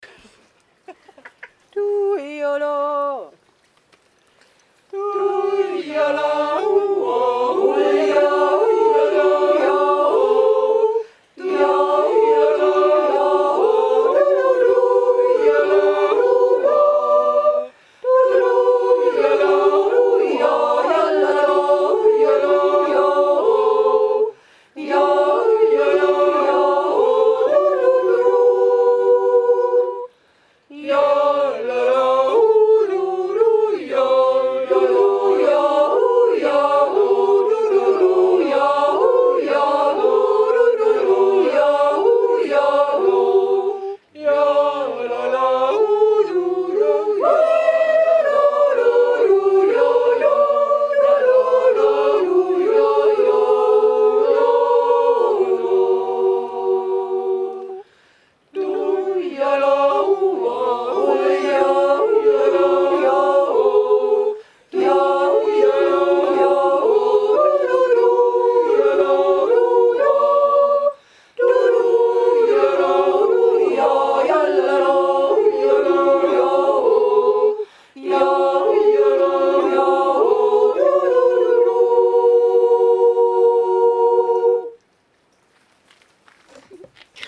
Allgäuer Doppeljodler
JA hier findet ihr die Aufnahmen unserer Jodler , von mir eingesungen.